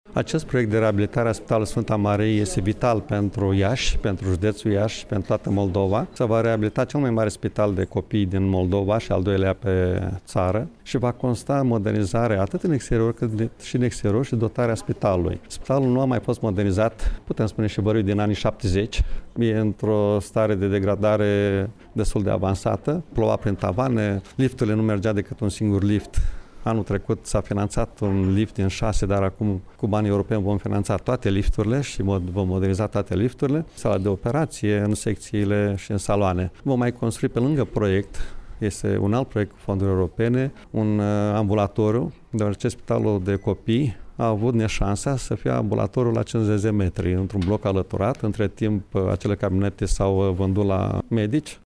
Președintele instituţiei, Maricel Popa a declarat că prin amploarea lui, proiectul se dorește a fi model pentru toate unitățile medicale din țară: